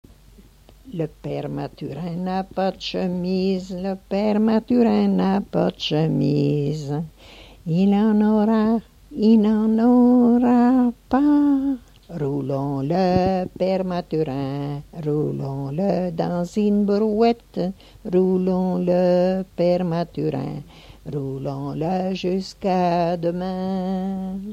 Genre énumérative
Enquête Arexcpo en Vendée
Pièce musicale inédite